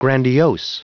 Prononciation du mot grandiose en anglais (fichier audio)
grandiose.wav